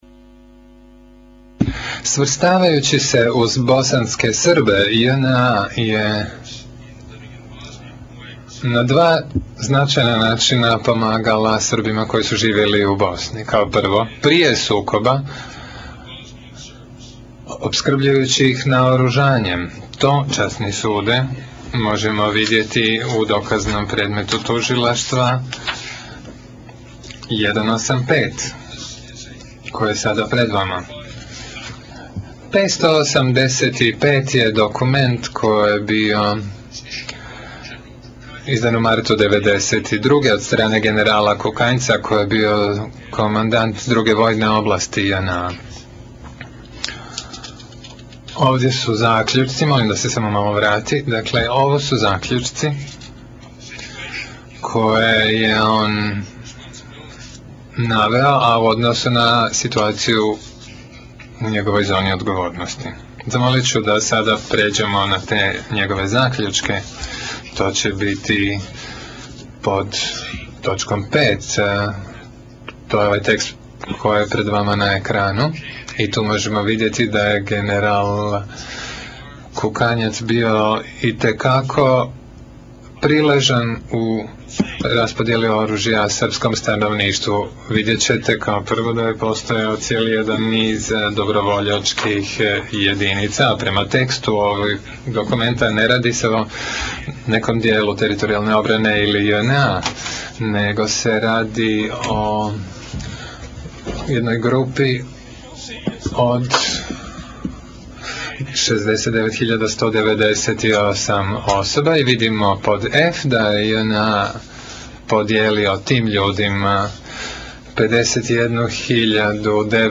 Tužitelj čita dokument JNA o naoružavanju bosanskih Srba